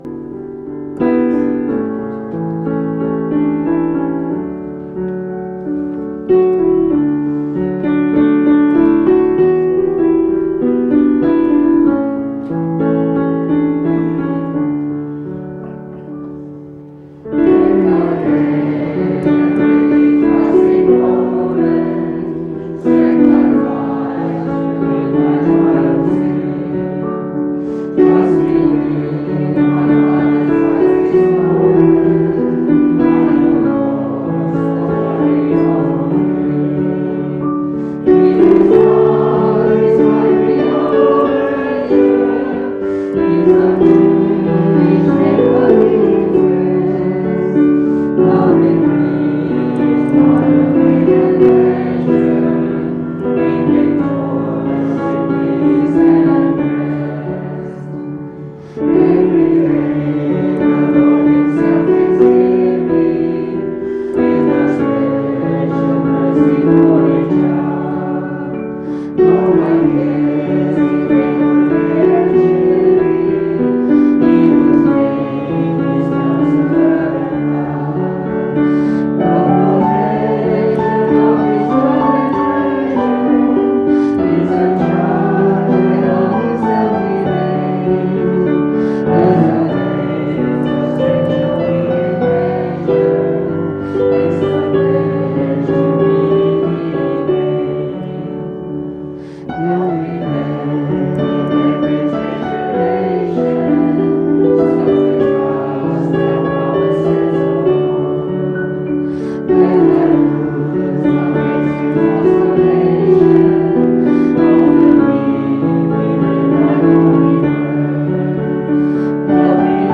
Special Performances
Sabbath Sermons 2020